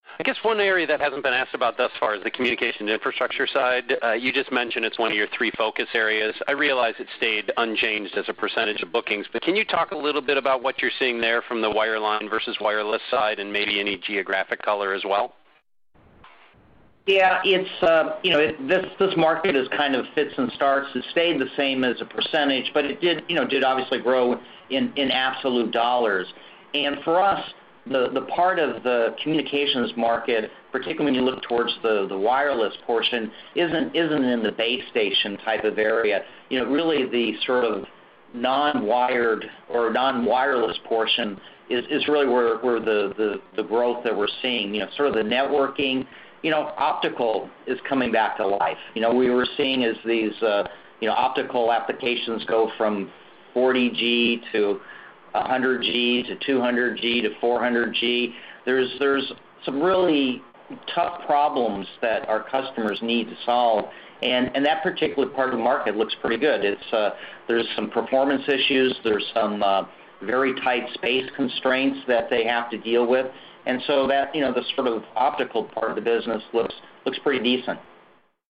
during the Q2 FY16 earnings call.